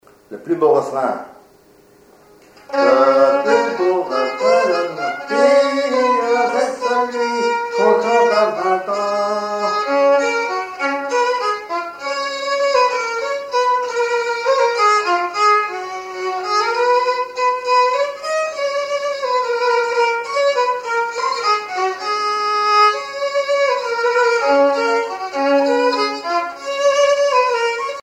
violoneux, violon
danse : valse musette
Pièce musicale inédite